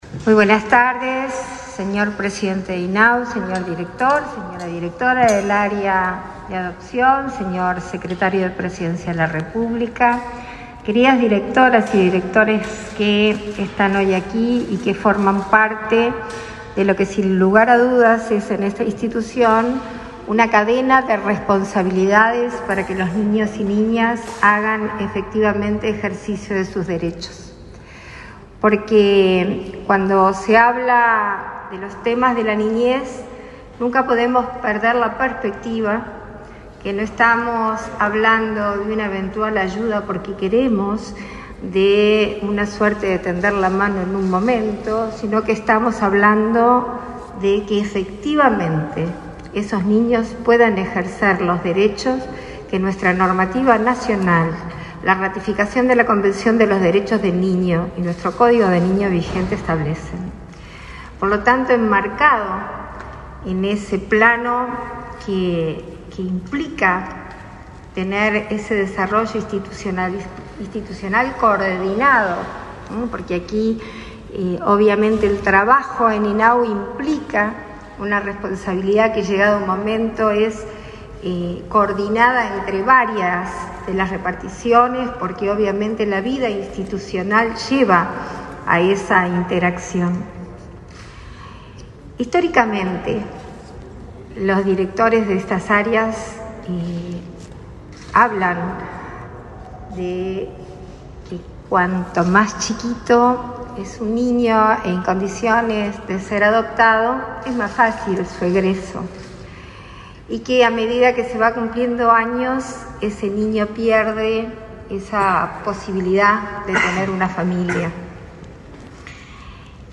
Palabras de la vicepresidenta de la República, Beatriz Argimón